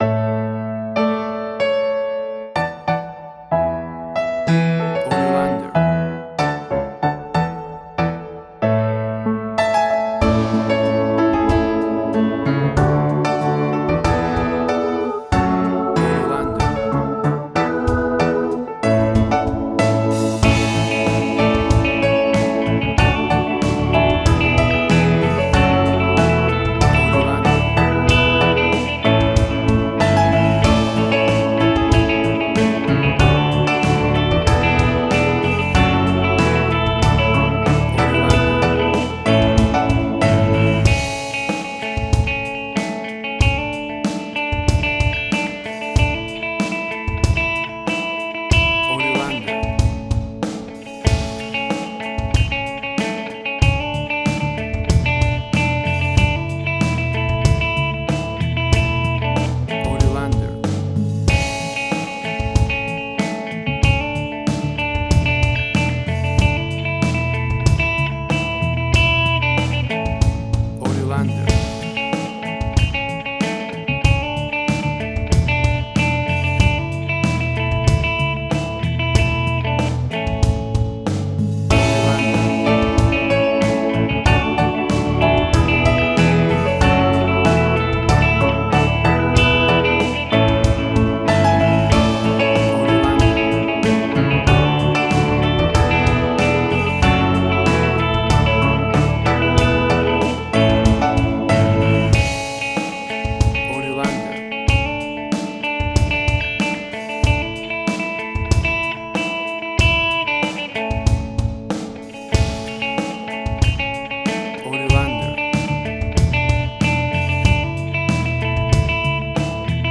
Tempo (BPM): 94